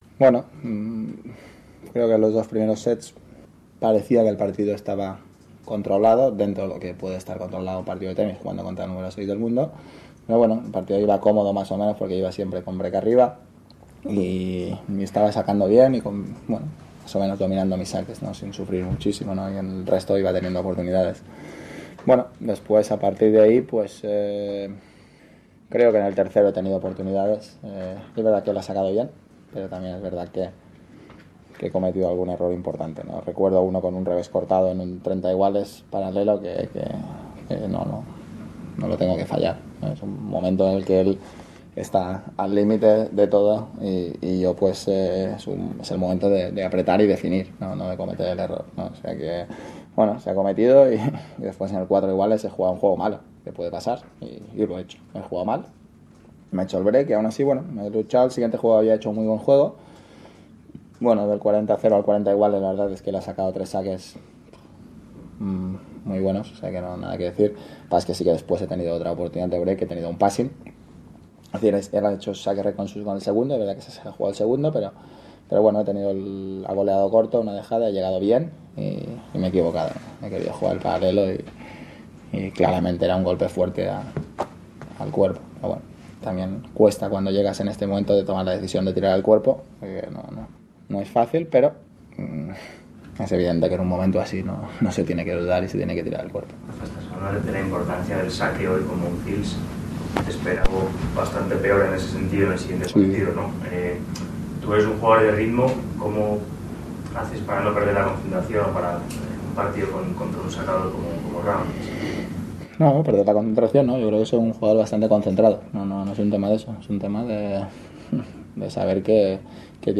Escucha aquí la entrevista en español con Rafael Nadal, quien analiza su partido con el francés Gael Monfis y el próximo encuentro con el canadiense Milos Raonic, en cuartos de final del Abierto de Australia 2017.